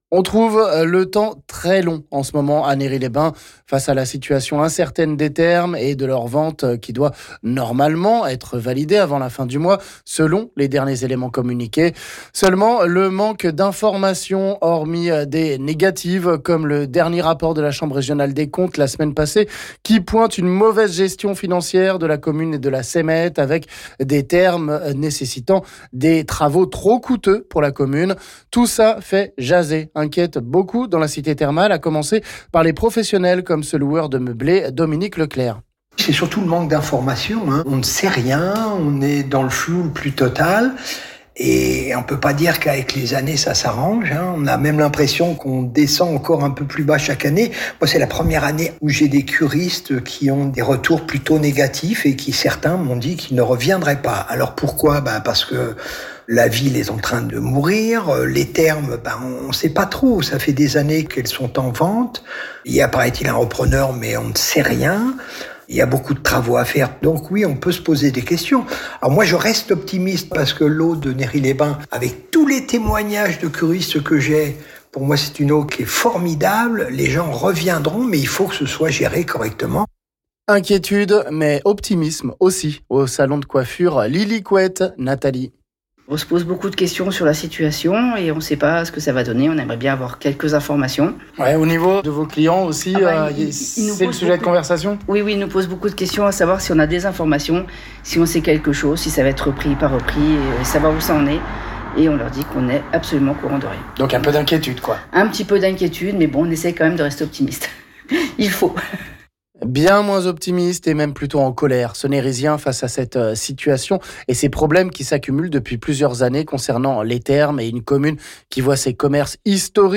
Etat des lieux et témoignages ici...